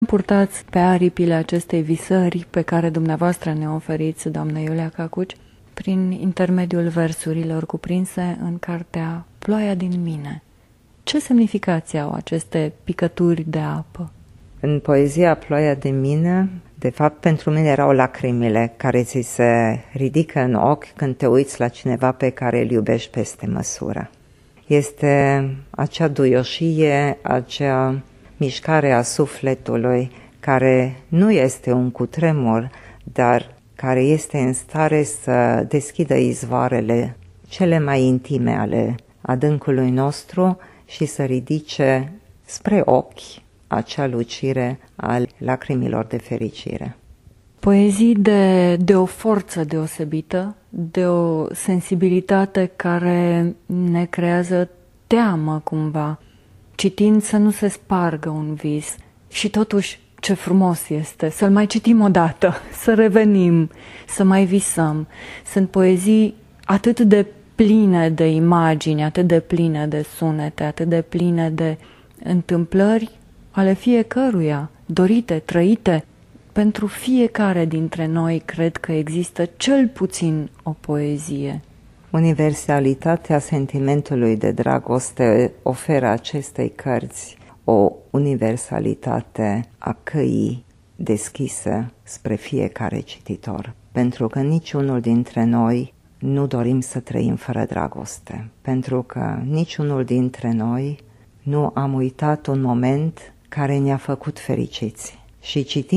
Reproducem doua fragmente din acest interviu: